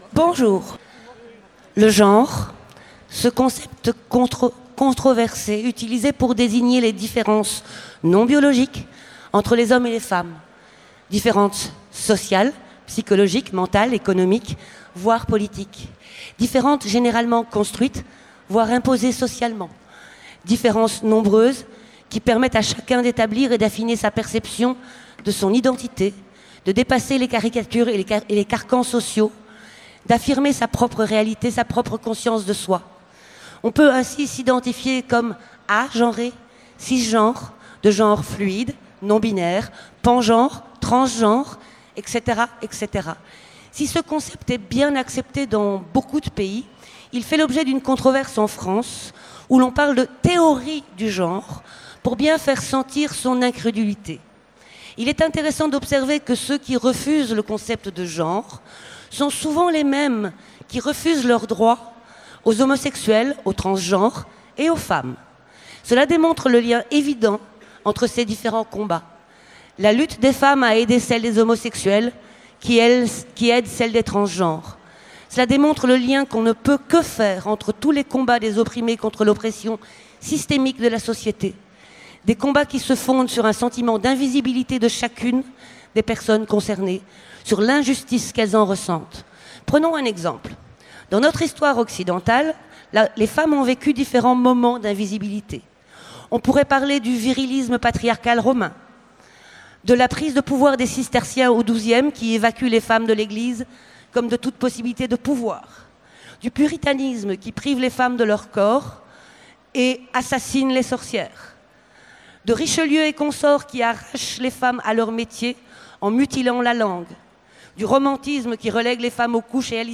Utopiales 2017 : Conférence Le temps du genre incertain